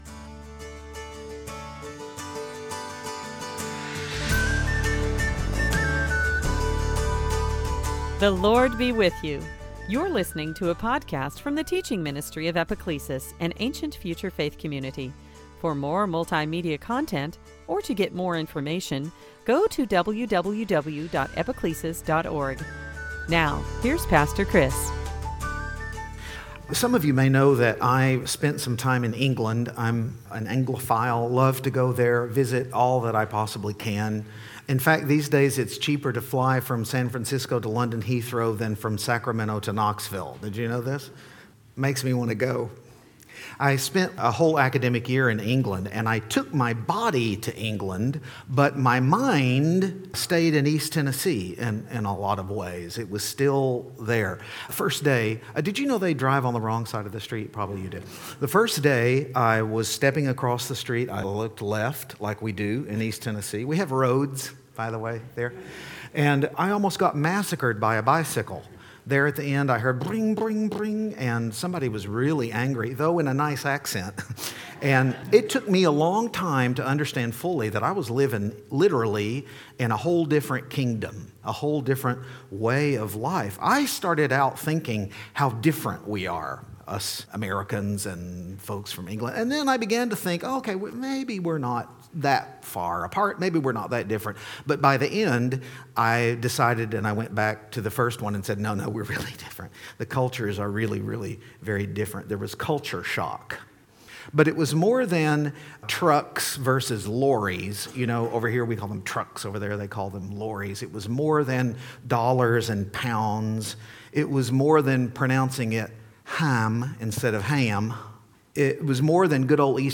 2022 Sunday Teaching Season after Pentecost